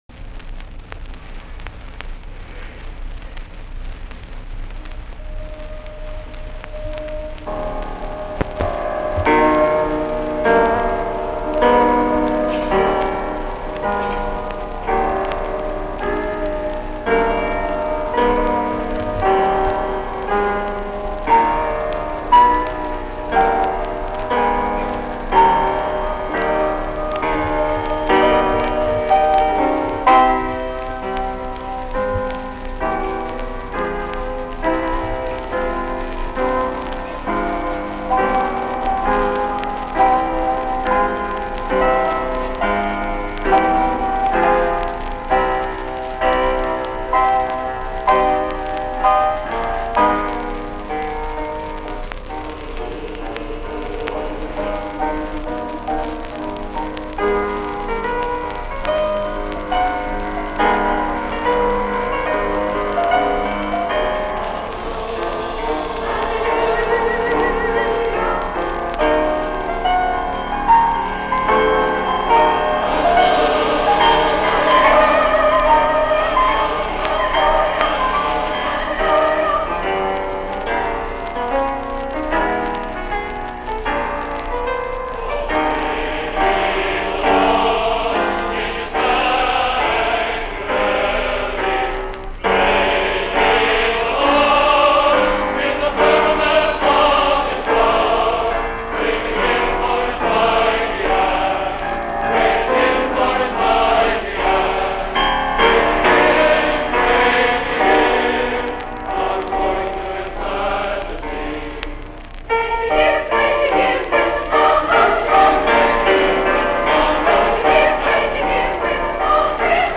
Click here to hear a very old recording of an unknown group performing César Franck's "Psalm 150." This was on a 78 RPM record of the kind used for home recording before magnetic tape.